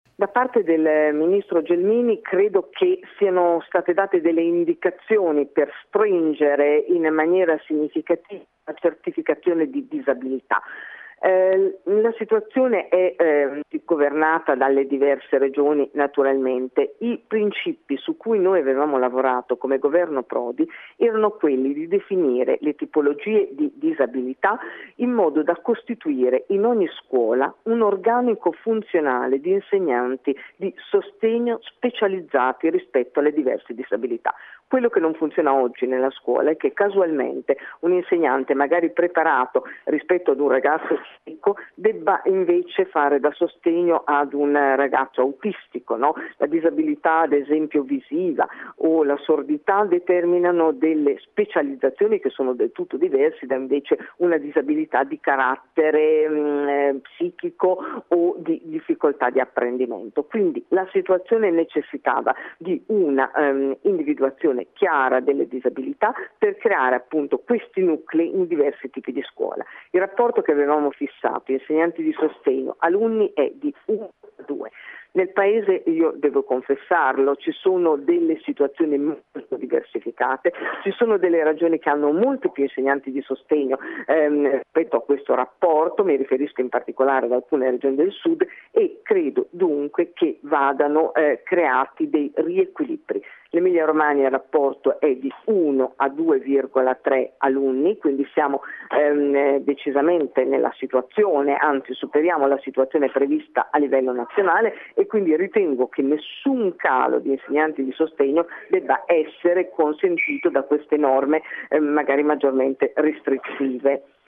Ascolta Mariangela Bastico, viceministro all’Istruzione nel precedente governo Prodi
Interviste